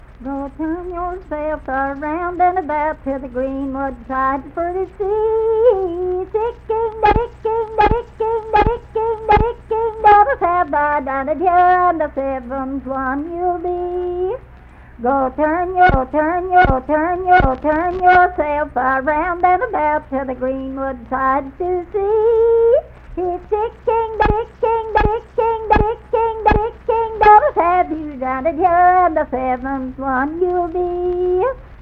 Unaccompanied vocal music
Verse-refrain, 2(4W/R).
Voice (sung)